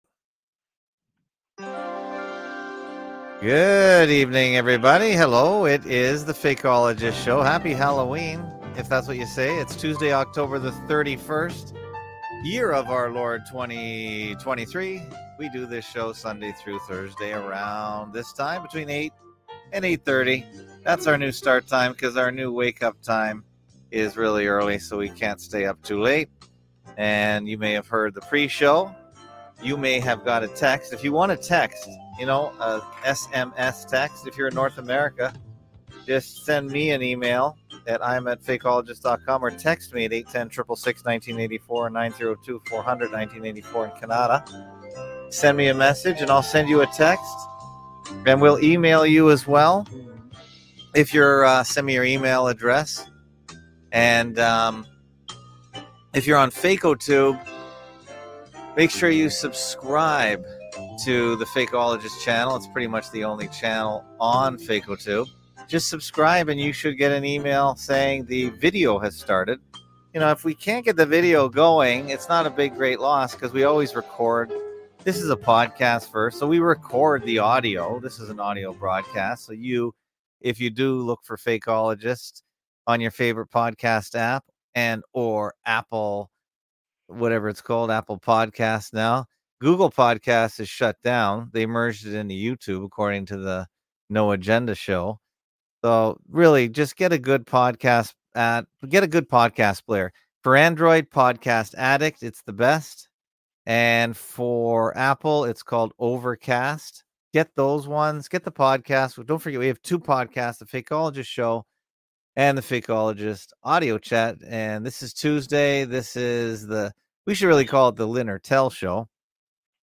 Category: Live Stream